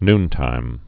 (nntīm)